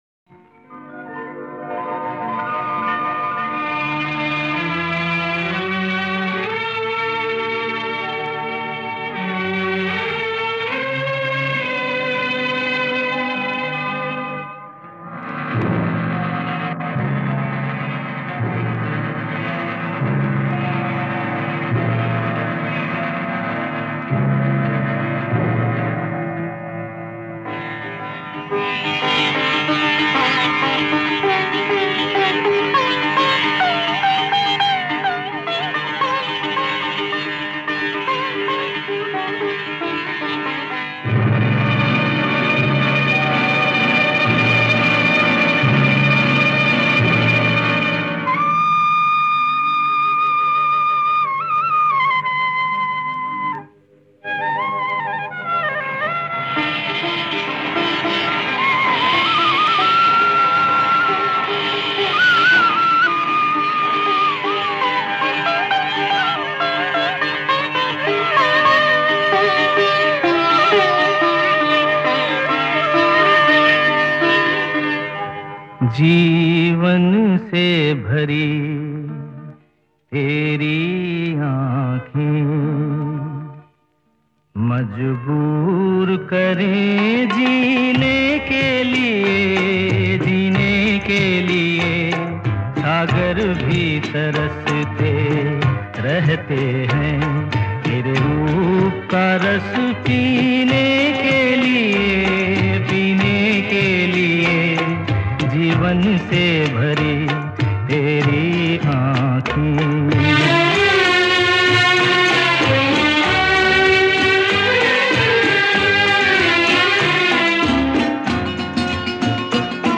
Soulfully sung by the